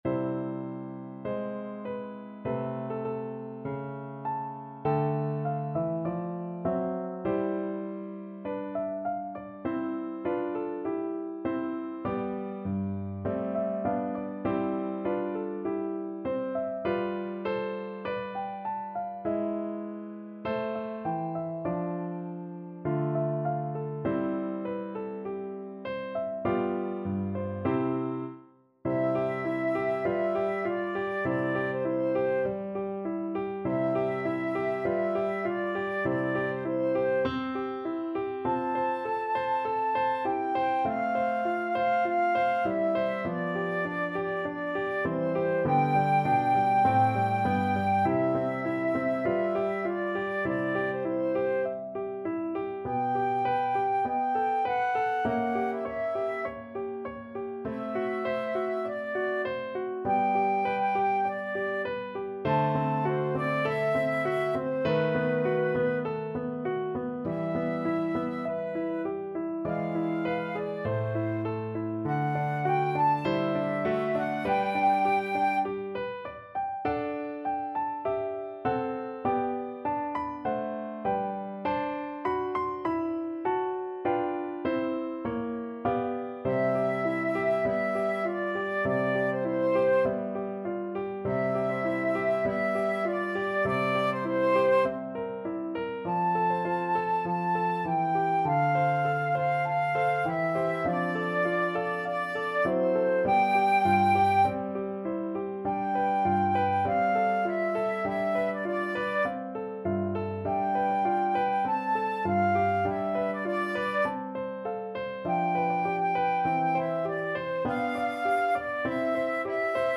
FlutePiano
=100 Poco lento
4/4 (View more 4/4 Music)
Flute  (View more Intermediate Flute Music)
Classical (View more Classical Flute Music)